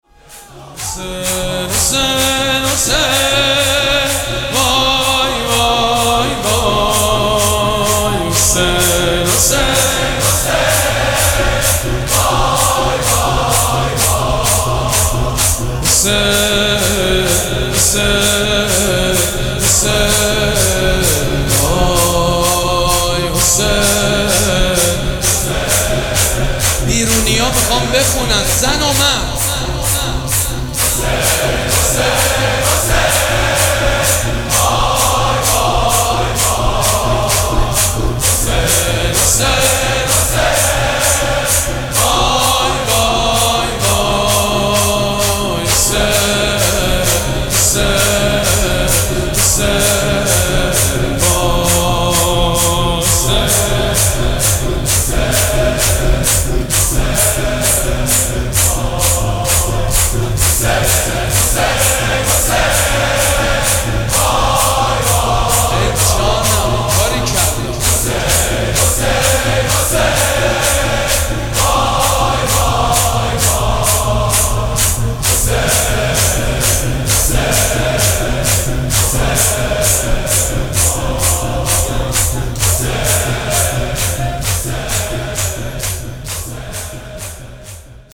محفل عزاداری شب چهارم محرم